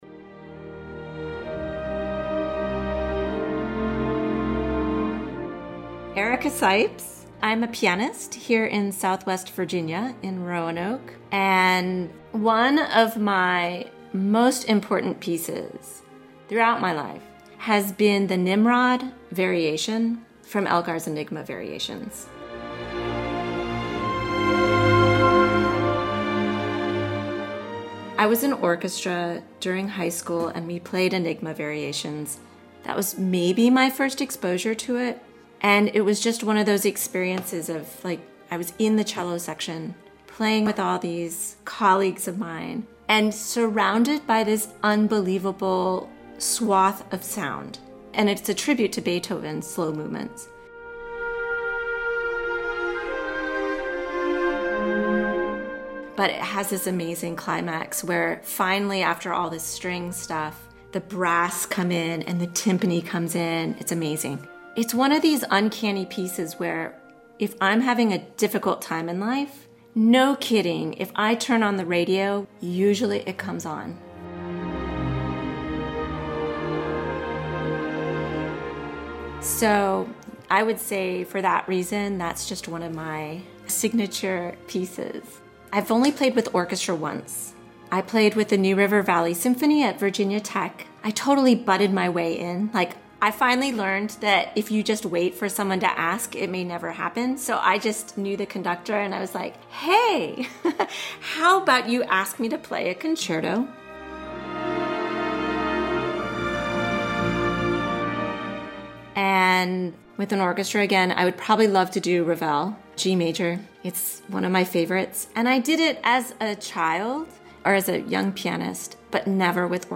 Classical Interviews